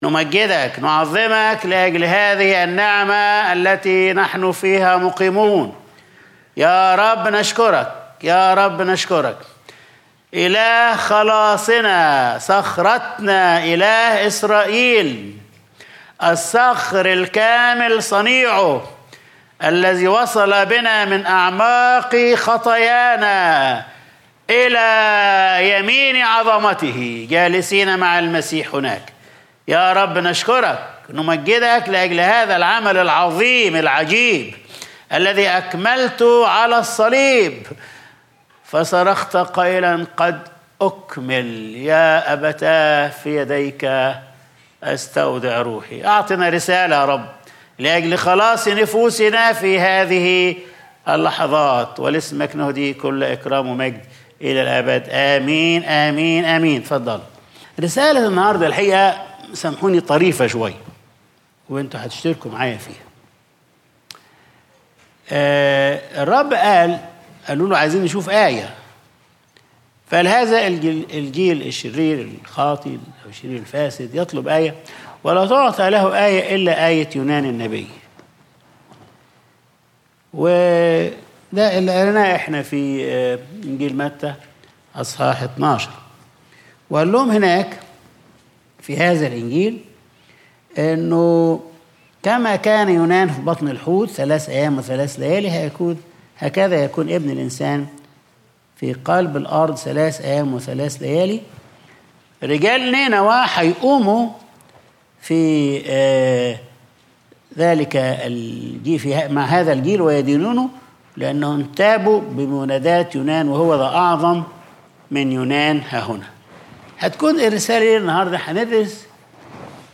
Sunday Service | هوذا أعظم مِن يونان ههُنا